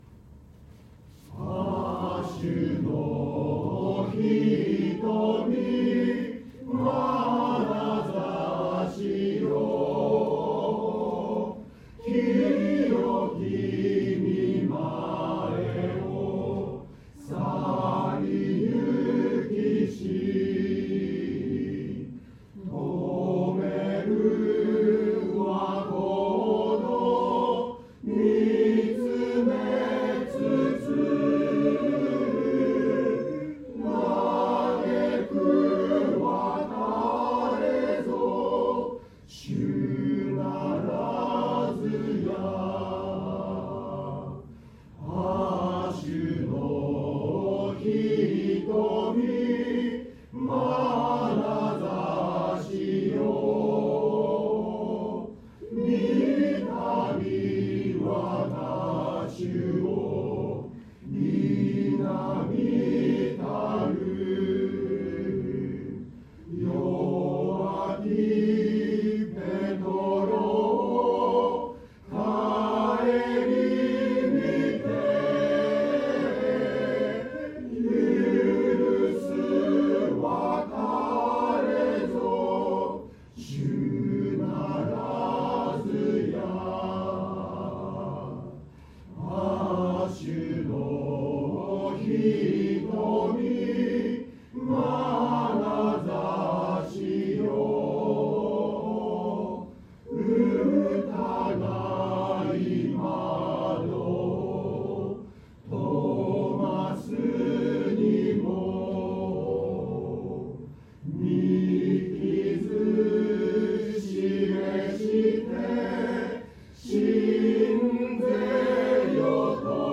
聖歌隊による賛美の歌声
礼拝での奉仕の様子